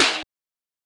SNARE 28.wav